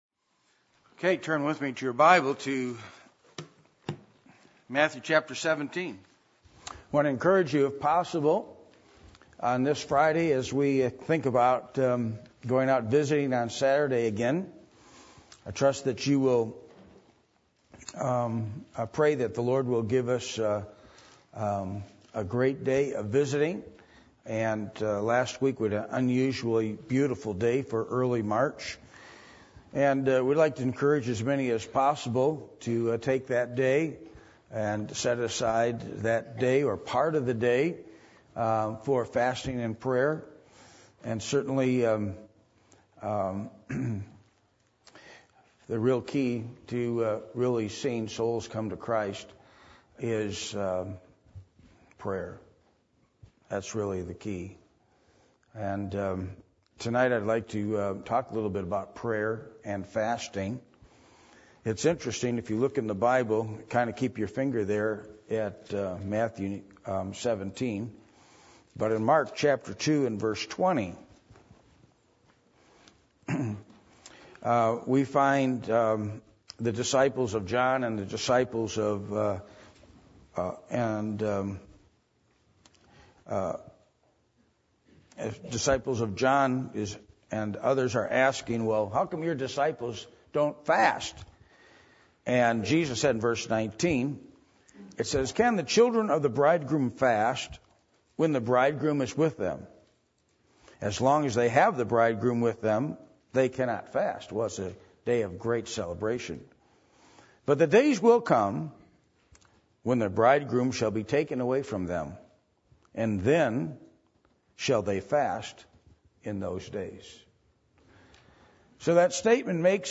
Mark 2:19-20 Service Type: Midweek Meeting %todo_render% « A Biblical Look At Clothing